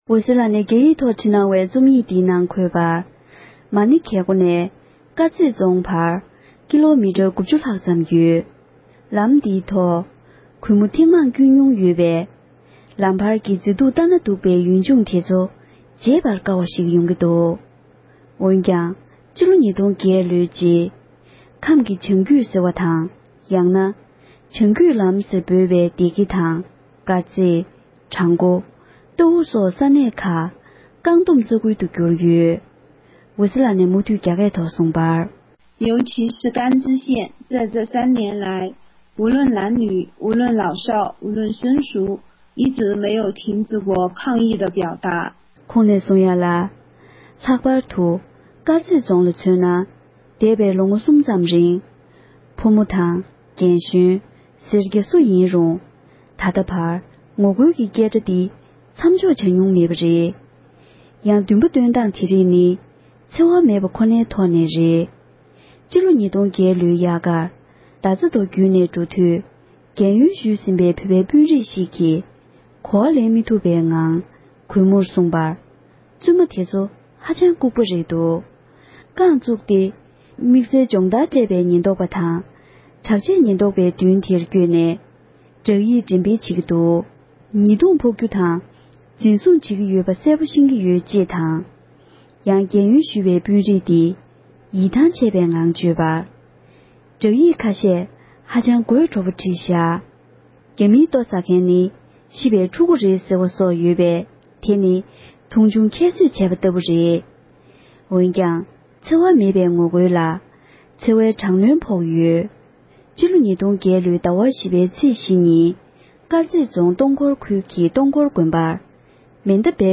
ཕབ་བསྒྱུར་དང་སྙན་སྒྲོན་ཞུས་པར་གསན་རོགས་ཞུ༎